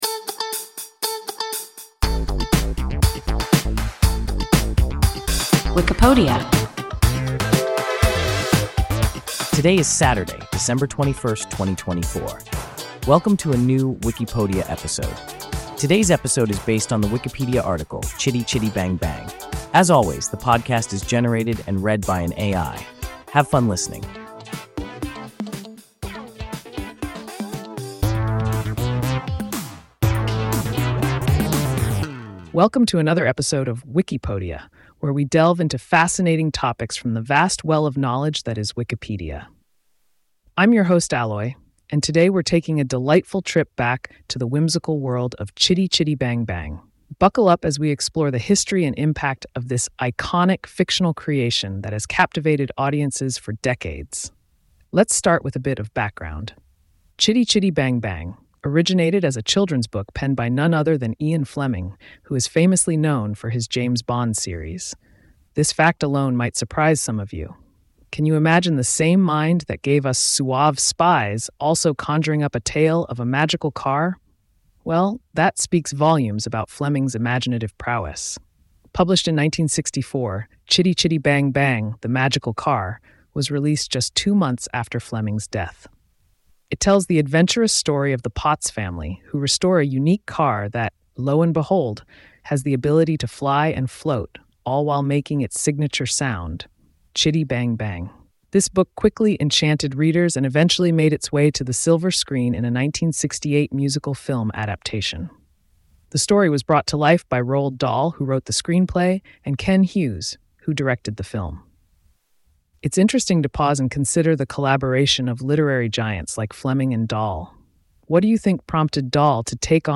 Chitty-Chitty-Bang-Bang – WIKIPODIA – ein KI Podcast